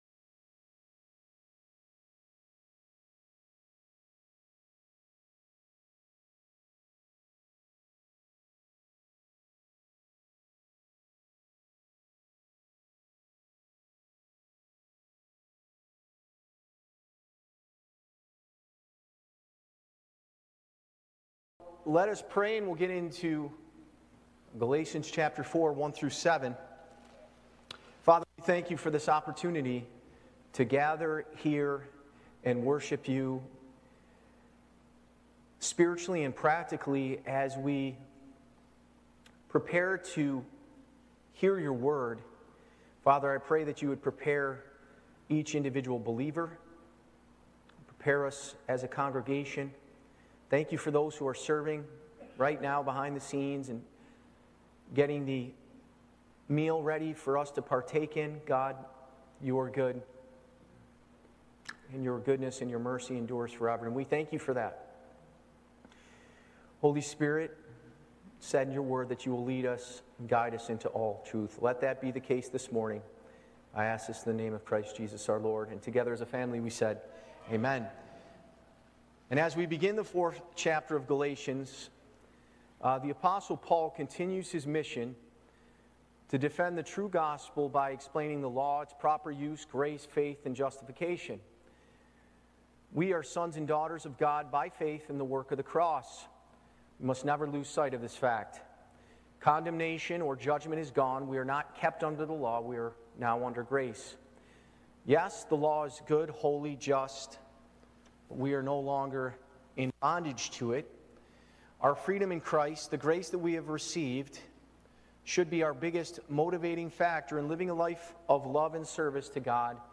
Church Location: Spencerport Bible Church
Live Recording